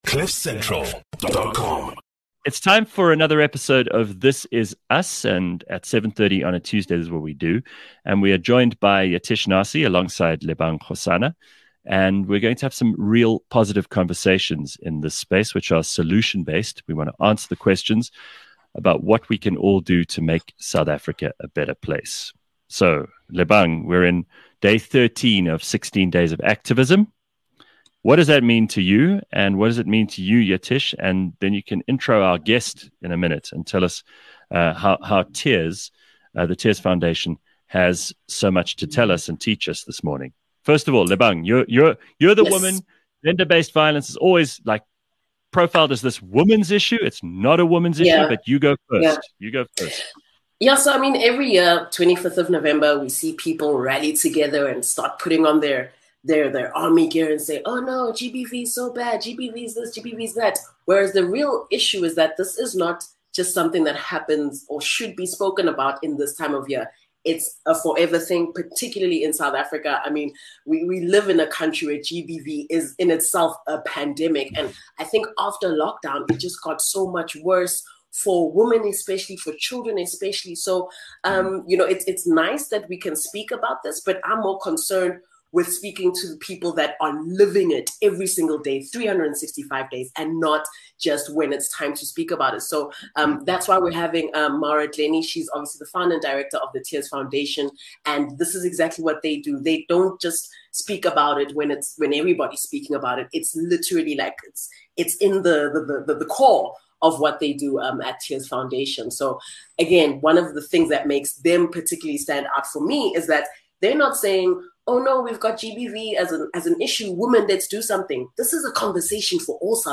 Since it’s 16 Days of Activism, the team have a candid conversation about the harsh reality of gender-based violence in our country.